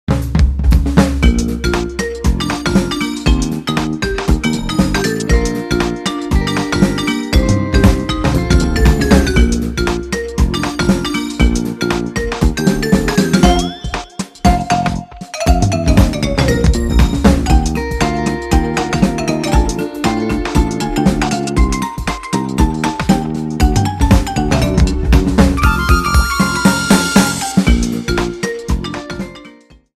Trimmed to 30 seconds and applied fadeout